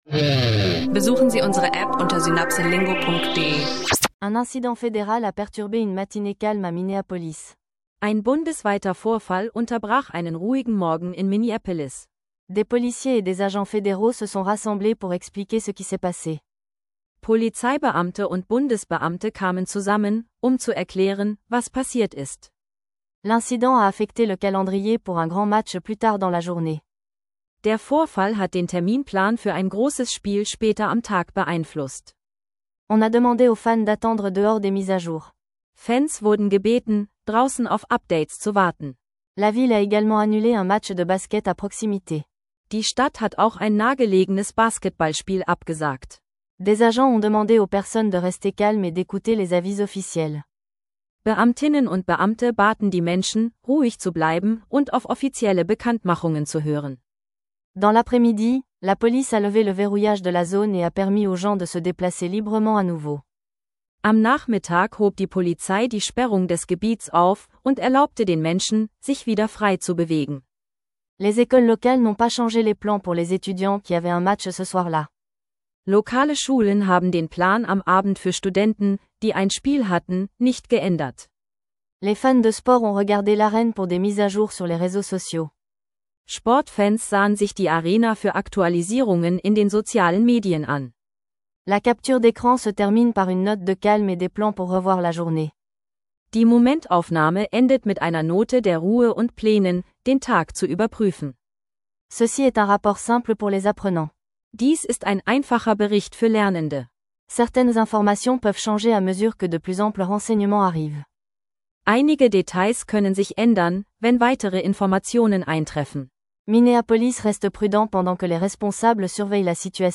In dieser Folge von Französisch lernen Podcast erwarten dich ein leichter Lesetext über Minneapolis und ein an Anfänger gerichteter Dialog zu Sport, Fitness und gesundem Leben – perfekt zum Frischlernen von Französisch lernen online.